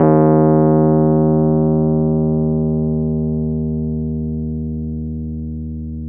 RHODES-EB1.wav